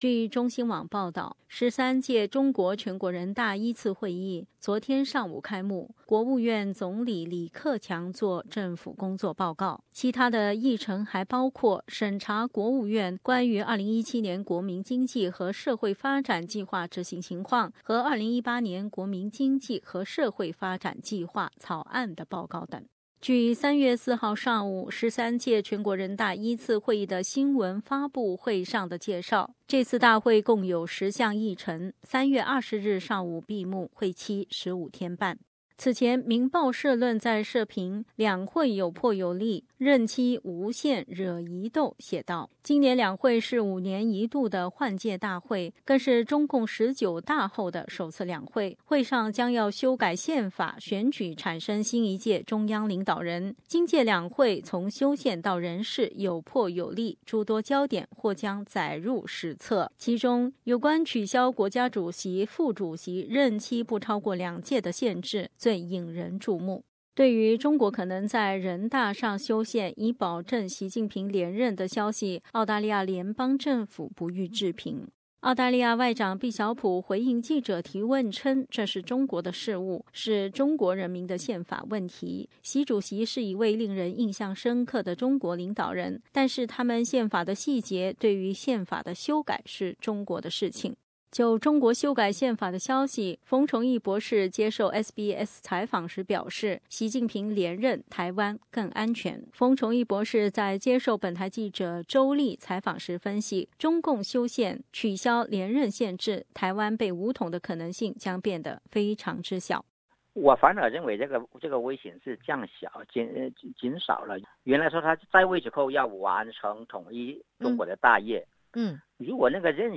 下面请听本台的综合报道： READ MORE 【两会2018】中国两会今日开幕，会期长于以往 【两会2018】外媒聚焦修宪，中媒关注经济 分享